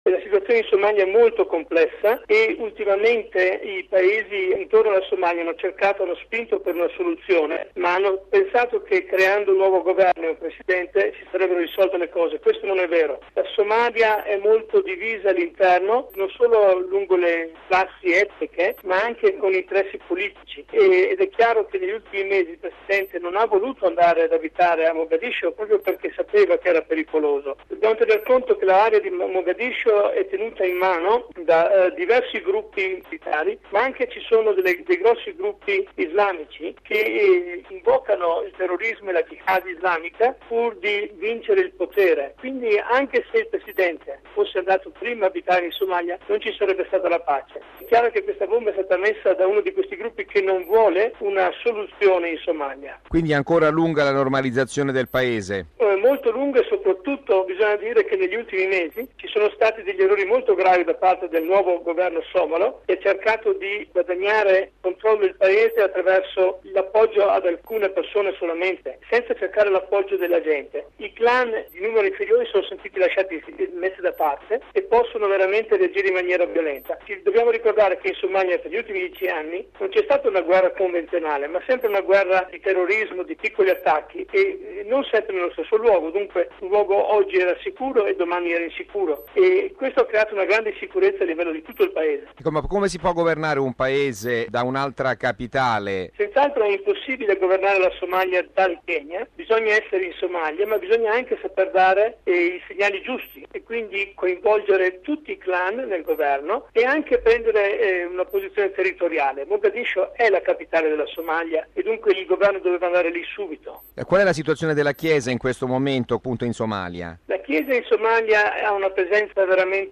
ha intervistato a Nairobi